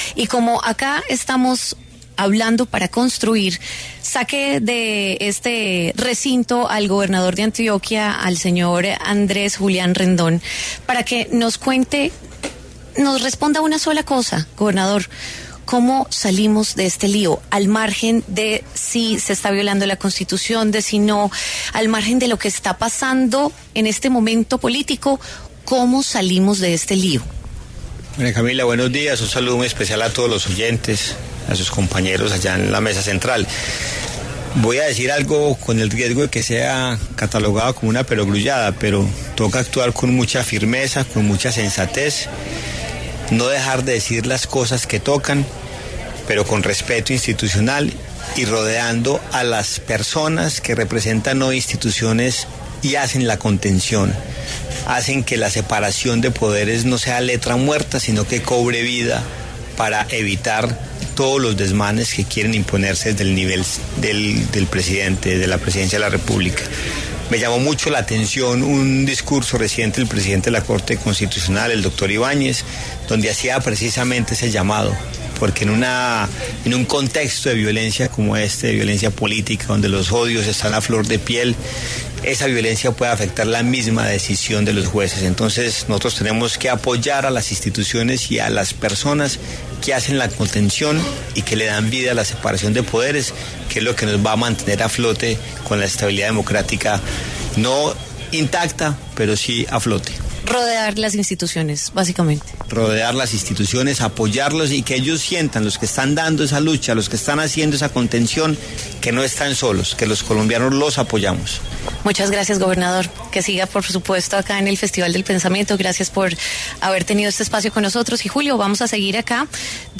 En el marco del Festival del Pensamiento de Prisa Media, La W pudo charlar con el gobernador de Antioquia, Andrés Julián Rendón, sobre temas de la coyuntura nacional.